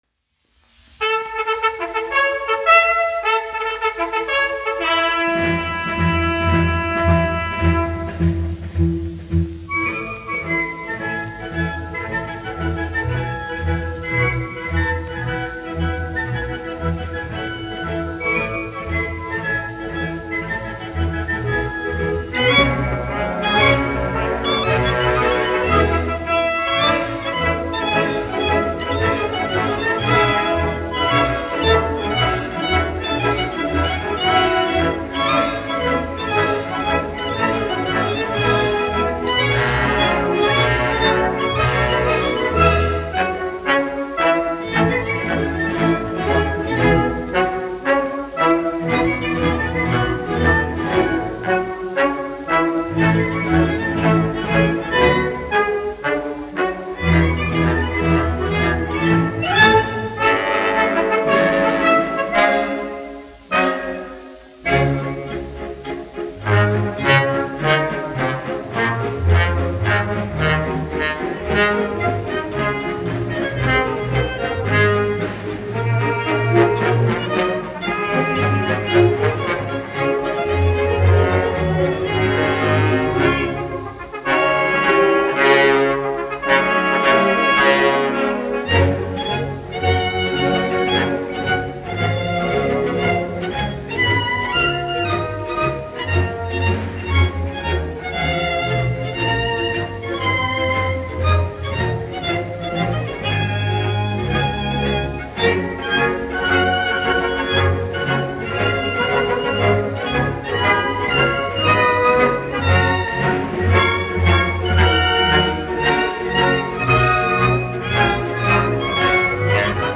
1 skpl. : analogs, 78 apgr/min, mono ; 25 cm
Marši
Orķestra mūzika
Skaņuplate